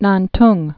(näntng)